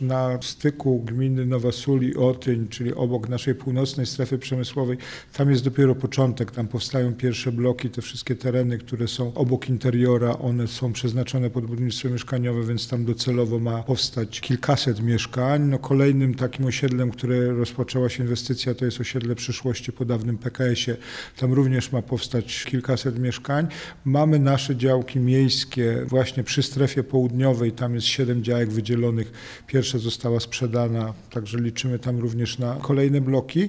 – W centrum miasta zostały zabudowane prawie wszystkie wolne miejsca, ale to nie koniec inwestycji developerów – powiedział prezydent Jacek Milewski: